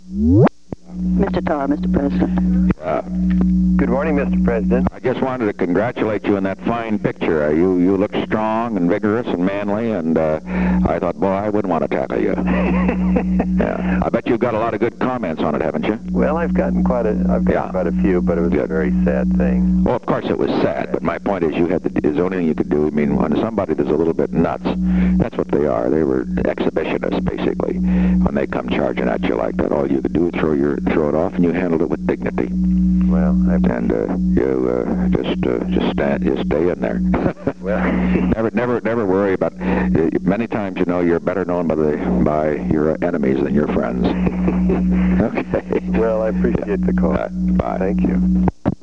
Secret White House Tapes
Location: White House Telephone
The President talked with Curtis W. Tarr.